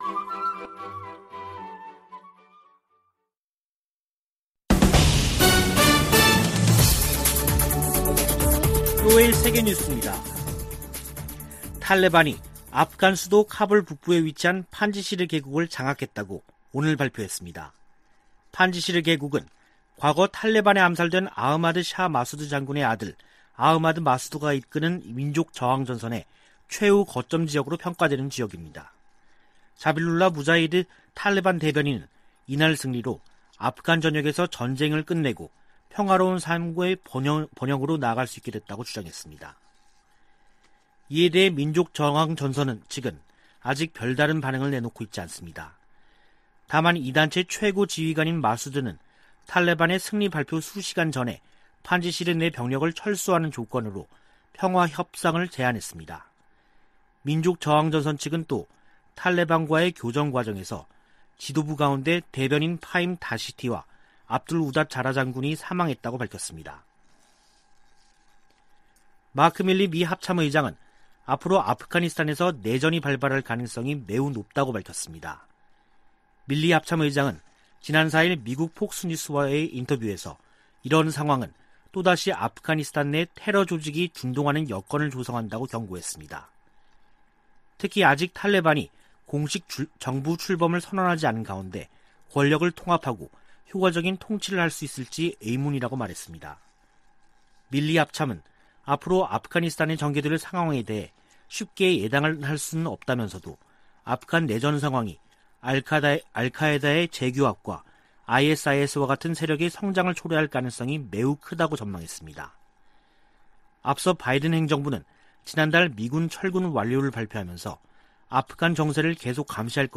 VOA 한국어 간판 뉴스 프로그램 '뉴스 투데이', 2부 방송입니다. 미 공화당 의원들은 북한 문제와 관련해 강력한 압박을 촉구하고 있는 가운데 일부 민주당 의원은 강경책은 해법이 아니라고 밝혔습니다. 북한이 대륙간탄도미사일 발사를 선택하더라도 이에 맞서 임무 수행할 준비가 돼 있다고 미 북부사령관이 밝혔습니다. 북한의 사이버 위협이 진화하고 있지만 미국의 대응은 제한적이라고 워싱턴의 민간단체가 지적했습니다.